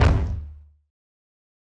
Waka Kick 2 (5).wav